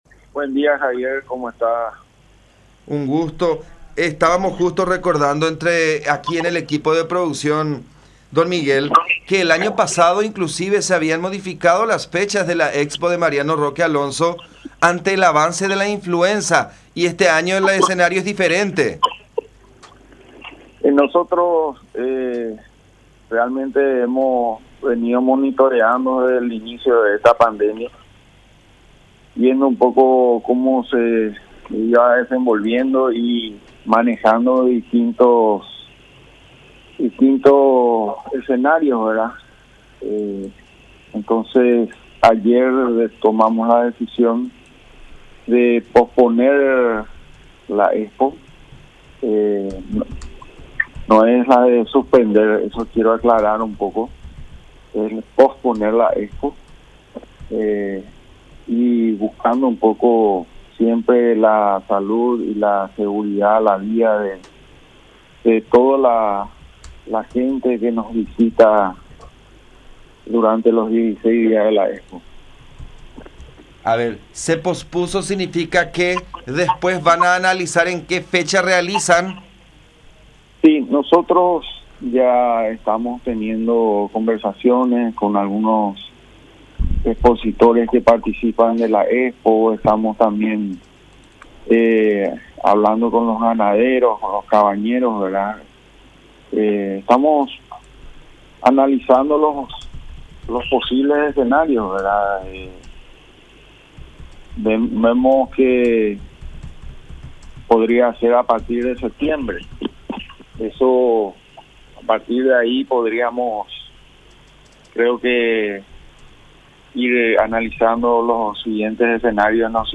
en conversación con La Unión.